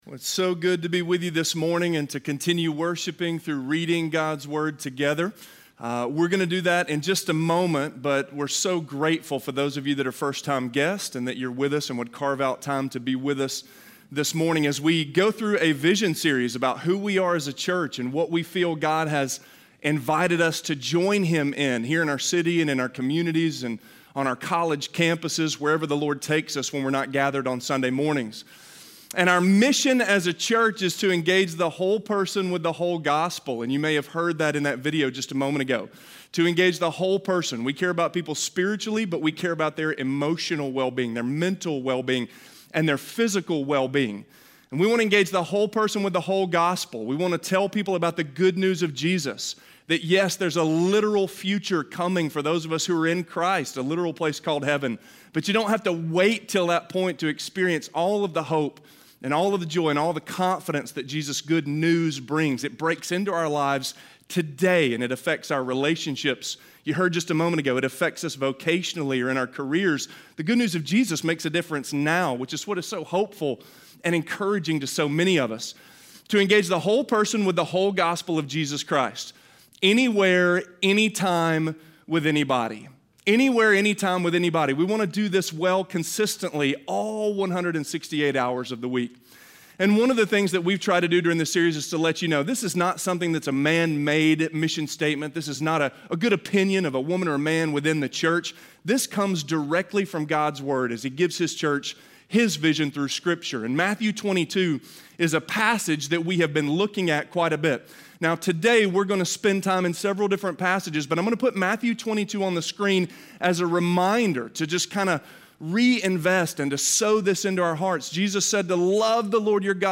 Loving God With All of My Soul - Sermon - Avenue South